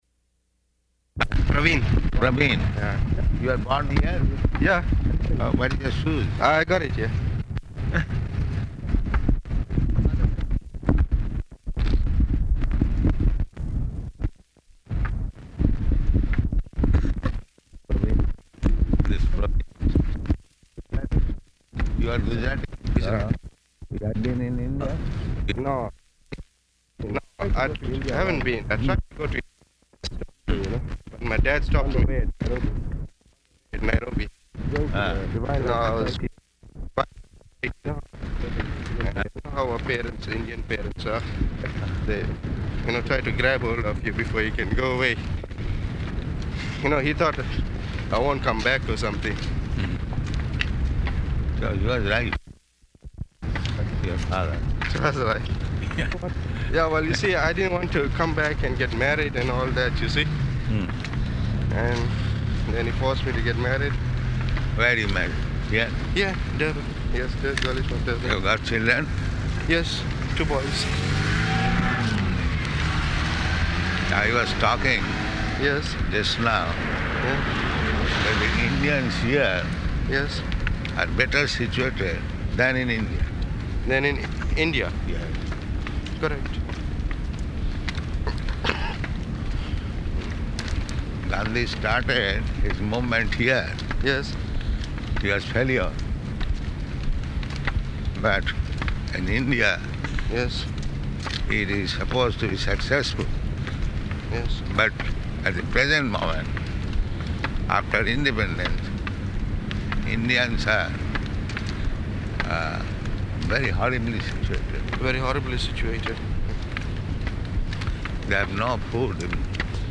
-- Type: Walk Dated: October 9th 1975 Location: Durban Audio file
[break] Indian man: No, I haven't been [indistinct; audio breaking up throughout]...my dad stopped...